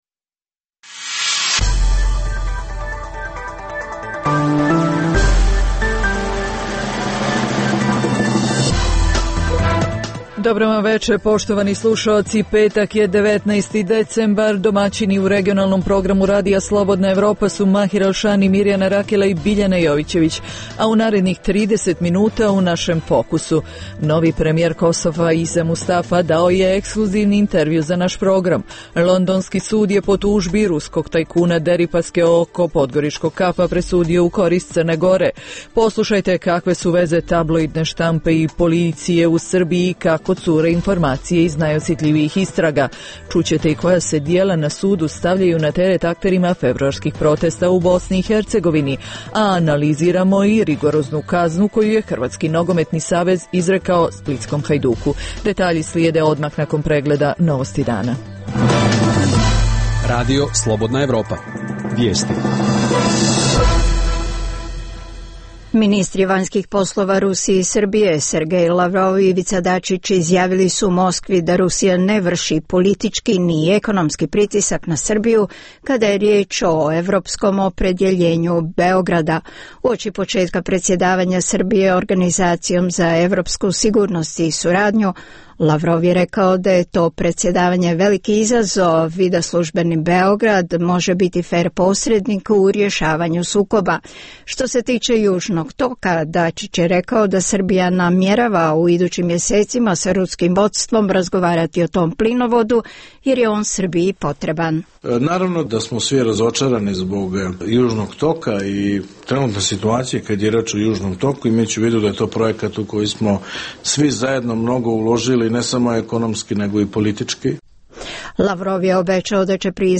U emisiji poslušajte: Novi premijer Kosova Isa Mustafa dao je intervju RSE Londonski sud je po tužbi ruskog tajkuna Deripaske oko KAP-a , presudio u korist Crne Gore Poslušajte kakve su veze tablodine štampe i policije i kako cure informacije iz najosjetljivijih istraga Čućete i koja se djela na sudu stavljaju na teret akterima februarskih protesta u BIH Hrvatski nogometni savez izrekao rigoroznu kaznu splitskom Hajduku